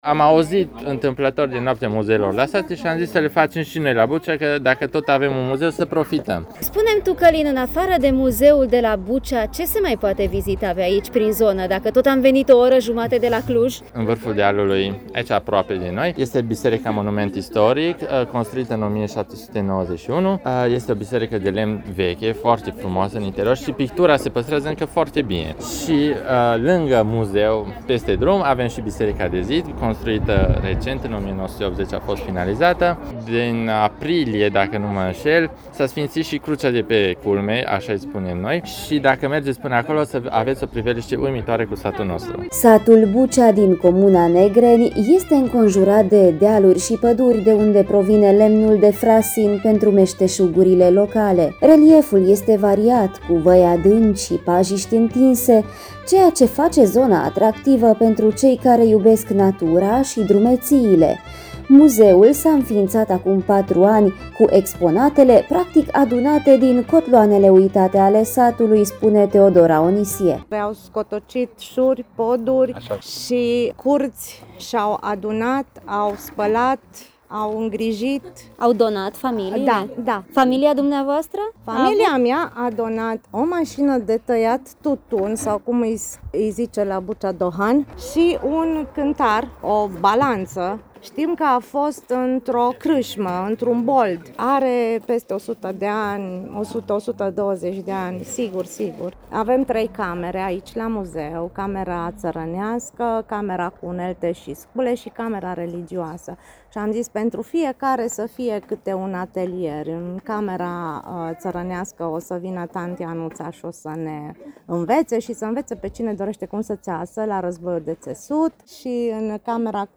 Meşteşuguri uitate şi tradiţii vii: Noaptea Muzeelor la Bucea - Radio Romania Cluj